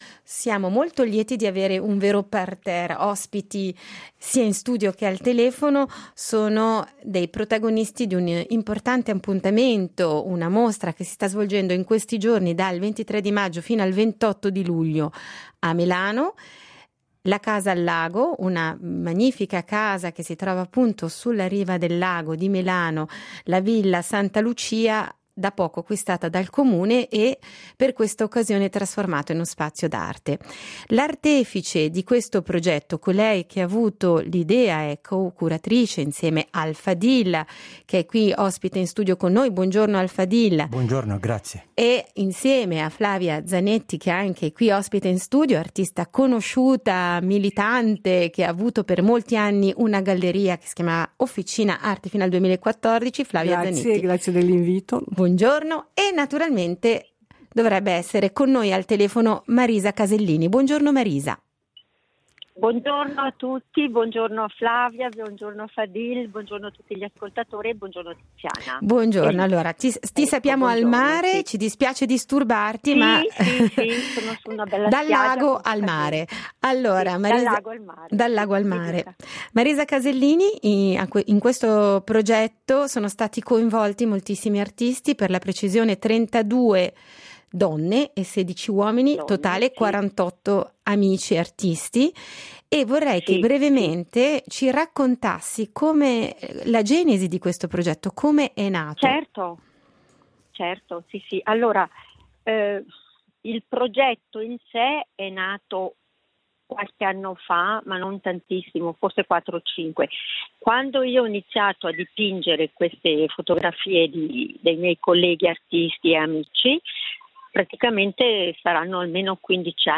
per parlarcene sono stati ospiti in diretta